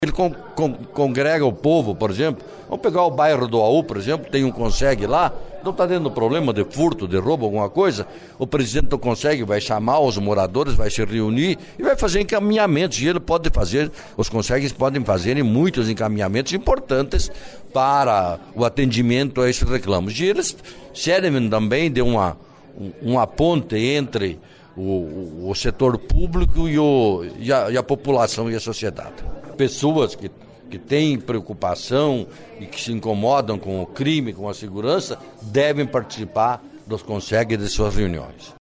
Os Conselhos de Segurança do Paraná têm um dia, o 2 de abril, para serem homenageados. O autor do projeto foi o deputado Delegado Recalcatti (PSD), que promoveu, no fim da tarde desta segunda-feira (2) uma sessão solene, onde homenageou os representantes dos Consegs. Ouça a entrevista do parlamentar...